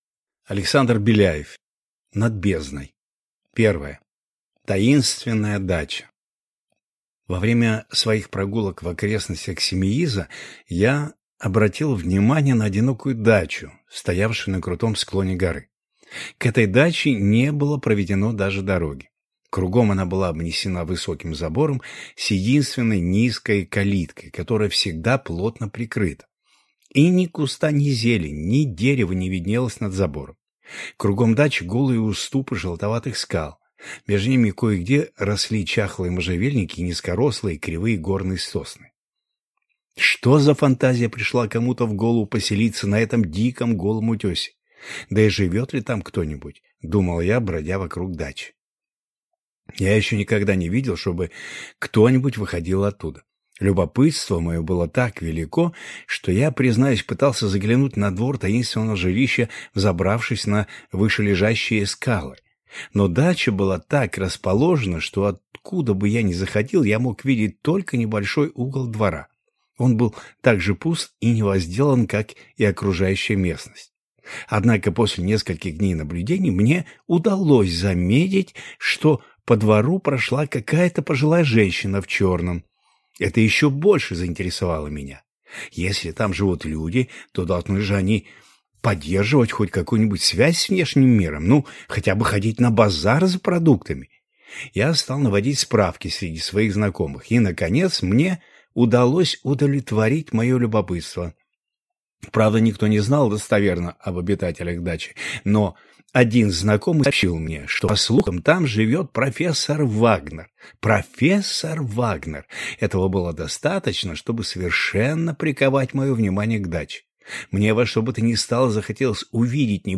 Аудиокнига Над бездной | Библиотека аудиокниг